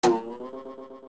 • Ironically, they are technically the same audio file, but the Slingshot version is slightly sped-up from the Bow's.
OOT_Bow_Twang.wav